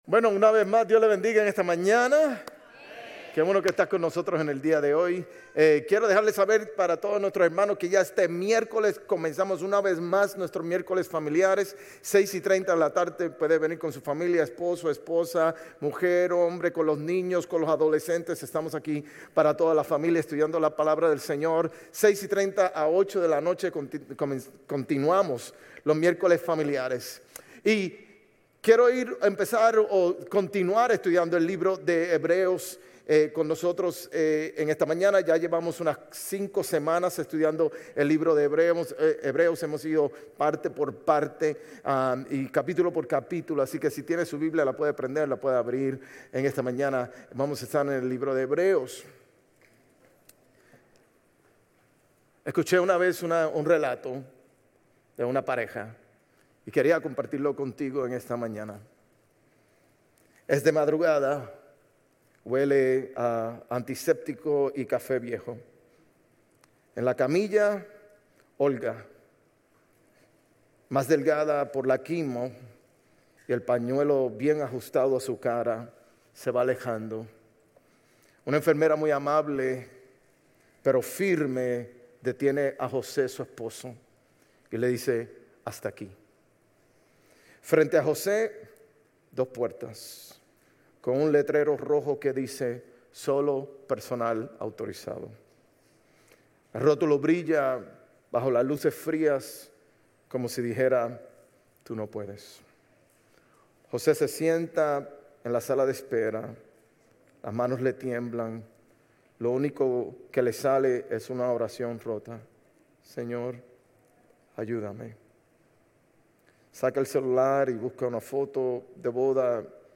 Sermones Grace Español 10_19 Grace Espanol Campus Oct 20 2025 | 00:42:19 1x 00:00 / 00:42:19 Subscribe Share RSS Feed Share Link Embed